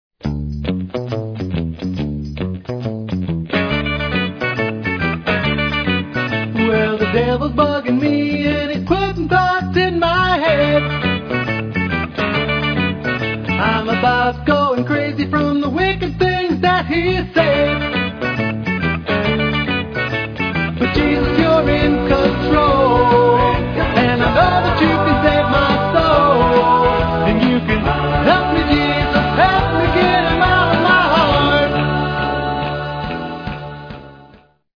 Christian lyrics written to the tune of popular songs
You will love the upbeat music and fun Christian message.